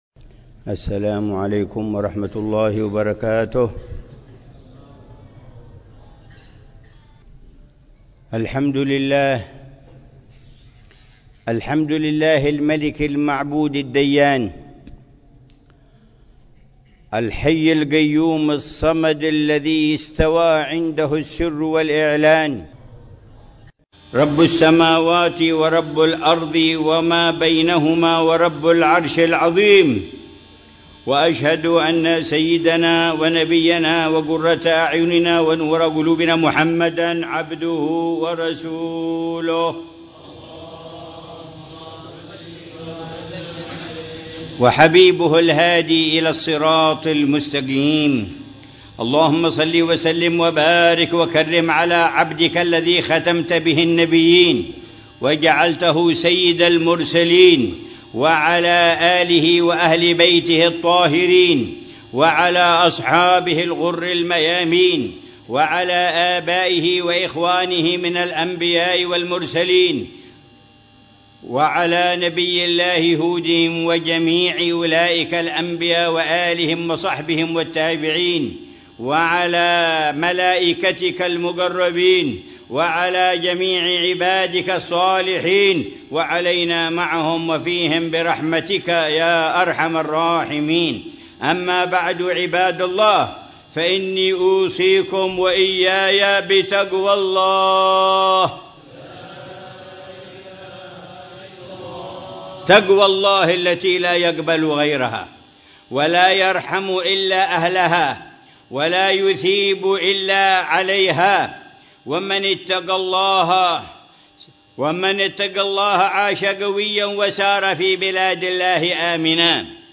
خطبة الجمعة للعلامة الحبيب عمر بن محمد بن حفيظ في جامع ديار آل بن كوب، شرقي قبر النبي هود عليه السلام ، بوادي حضرموت، 8 شعبان 1446هـ بعنوان: